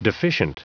Prononciation du mot deficient en anglais (fichier audio)
Prononciation du mot : deficient